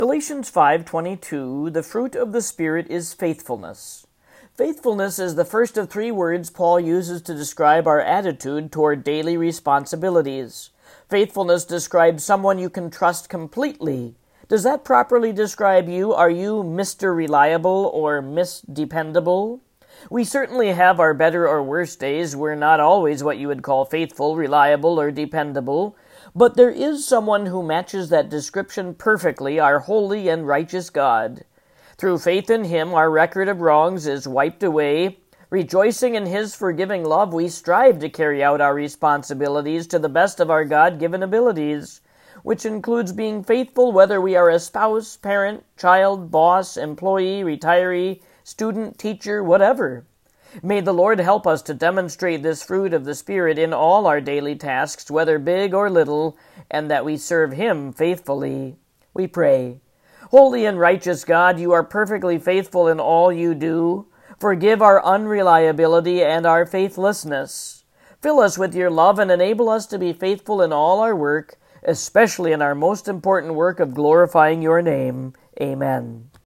1 minute devotions and prayers to keep you connected to God’s Word